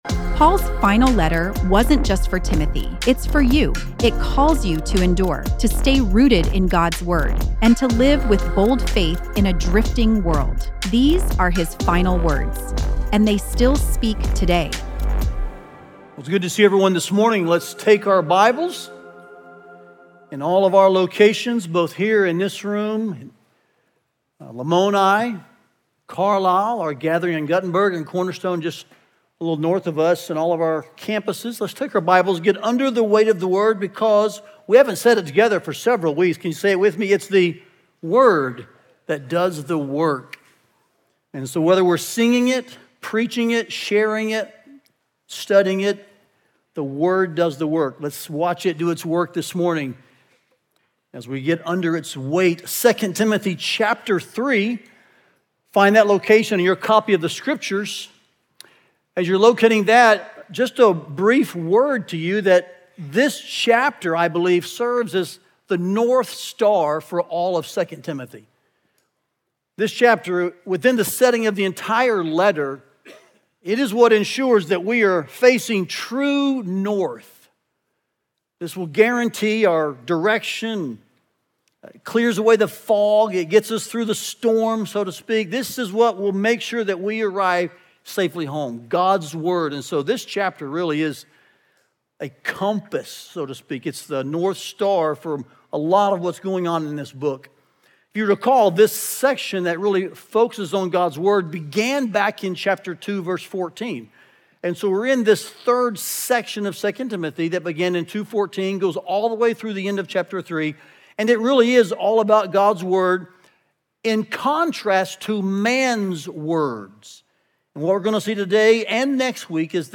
Listen to the latest sermon from our 2 Timothy series, “Final Words”, and learn more about the series here.